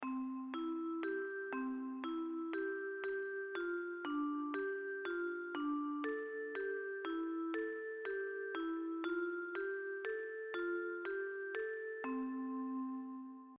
textual bahnhofplatz Im zugeordneten Tonraum werden für die drei Gruppen «Melodien» komponiert.
Ortskundigen sind regelmässig und repetitiv.
klingender_bahnhofplatz_kennen.mp3